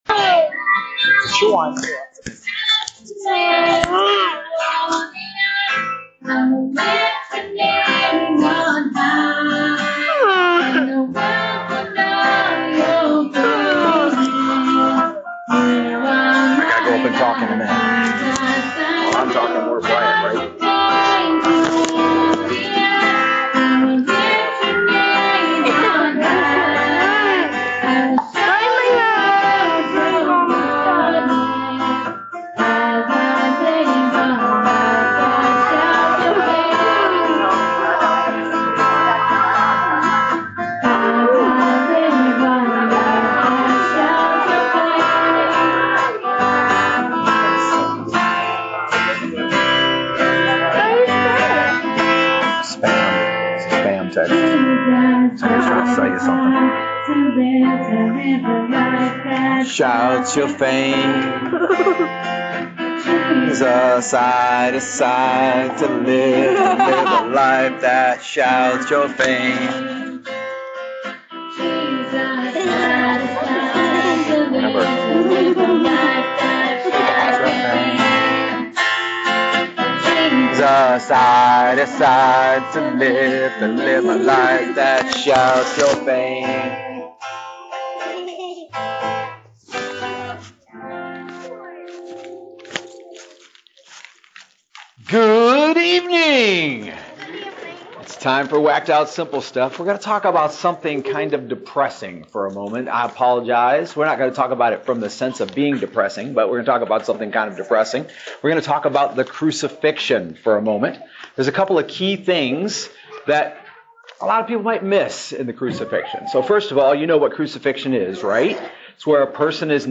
In this Bible Study we look at three promises that allow us to soar above life's concerns and the commands of Christ that seem to be part and parcel with them…